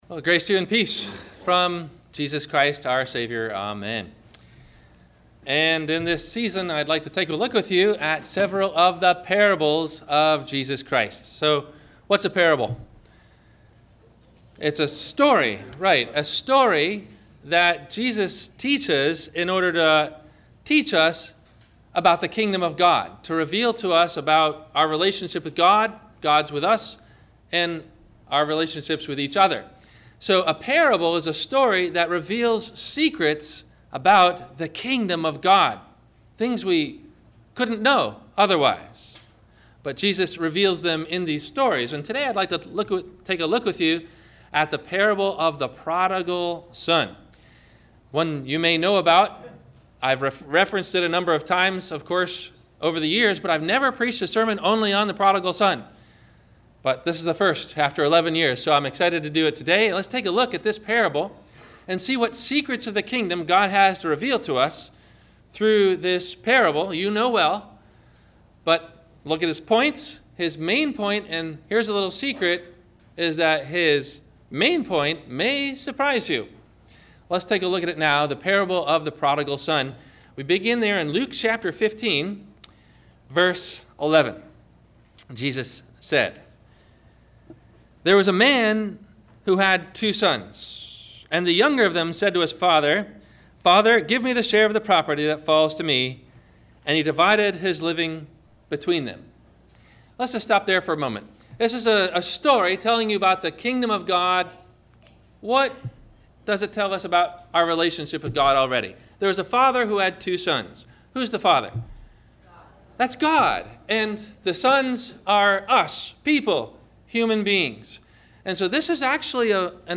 The Prodigal Son - 1st Sunday Advent - Sermon - November 27 2016 - Christ Lutheran Cape Canaveral